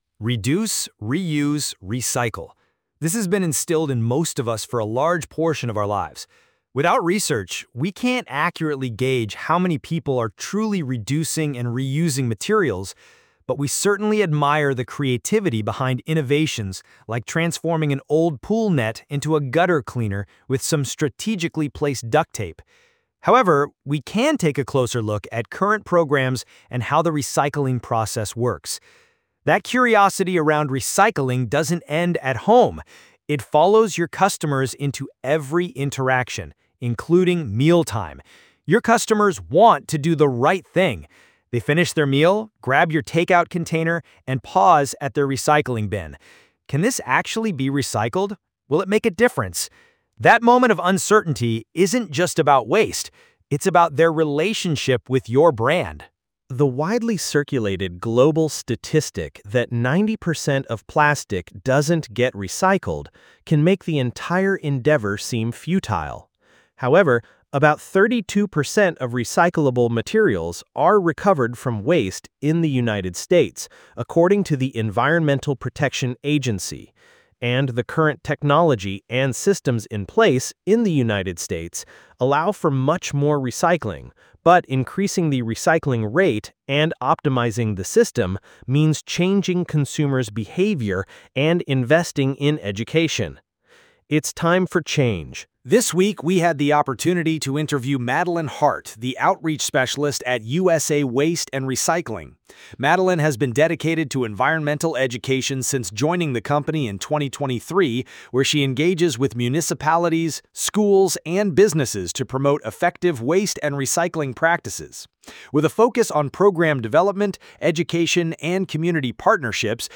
Listen to the audio recording of this blog post below.
Do-You-Know-What-Happens-to-Your-Recycling-When-It-Gets-Picked-Up-from-the-Curb-Dictation.mp3